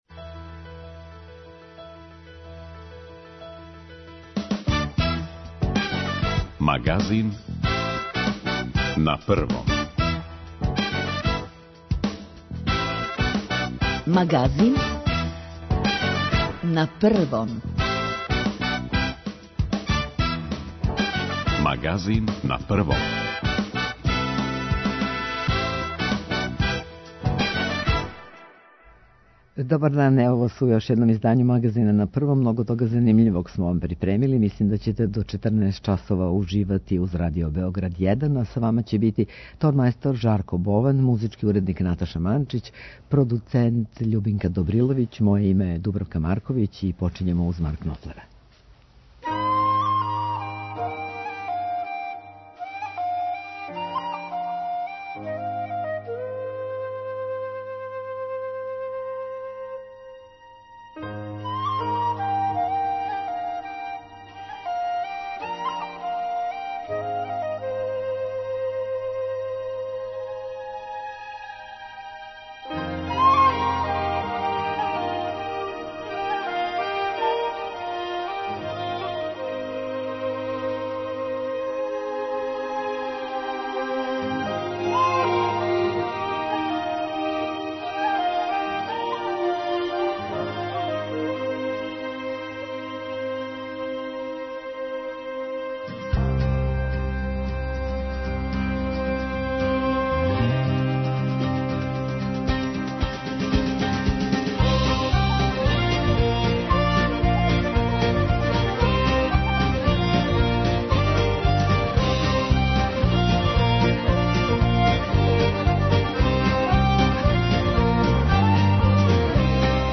Гост емисије је министар спољних послова Републике Србије, Ивица Дачић. Говориће о актуелним дешавањима када је у питању наша спољна политика.